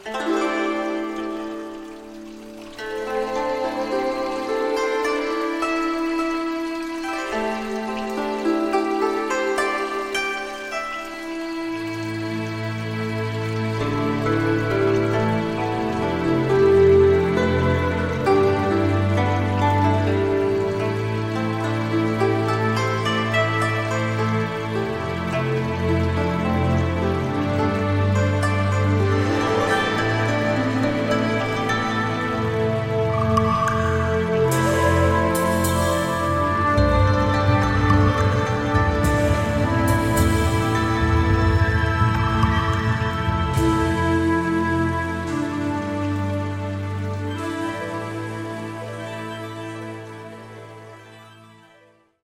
A reverent musical tribute to the Ascended Masters
a unique blend of Oriental & Western musical traditions
Mastered with 444 Hz Solfeggio Frequencies.